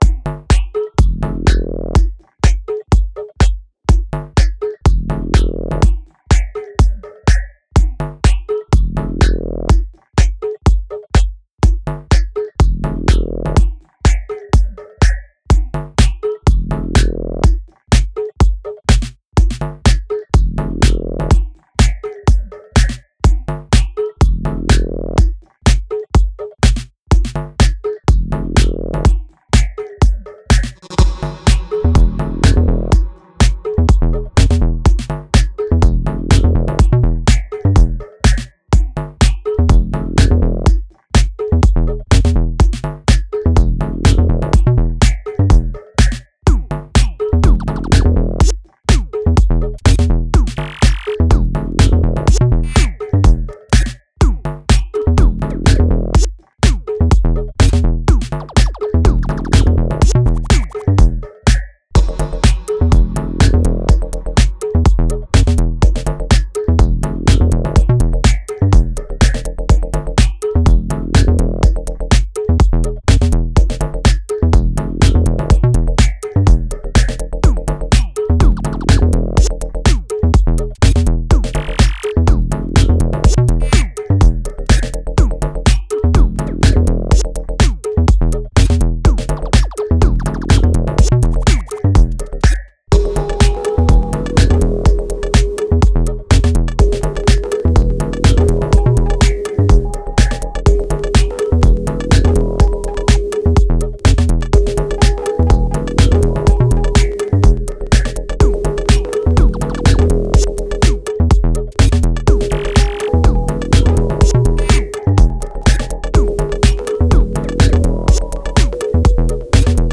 Genre: Psychill, Downtempo, IDM.